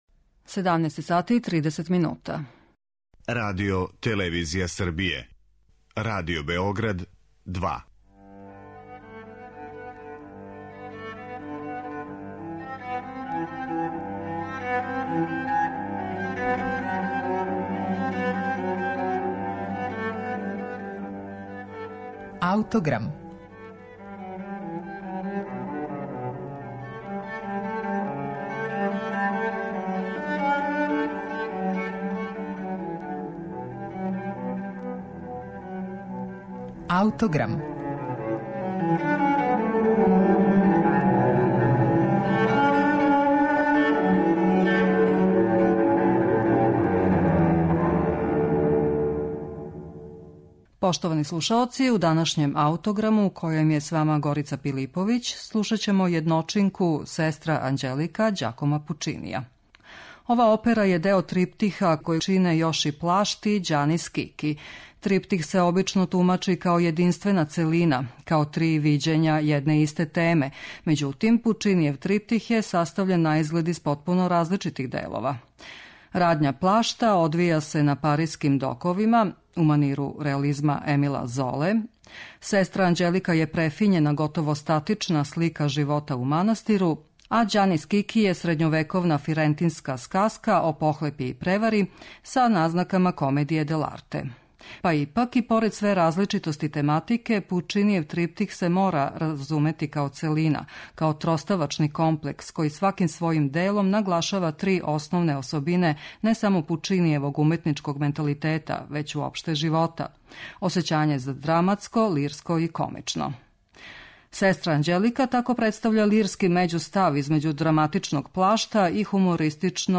У данашњем Аутограму можете слушати средишњи, лирски део триптиха - причу о несрећној жени која је послата у манастир зато што је родила ванбрачно дете.